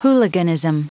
Prononciation du mot hooliganism en anglais (fichier audio)
Vous êtes ici : Cours d'anglais > Outils | Audio/Vidéo > Lire un mot à haute voix > Lire le mot hooliganism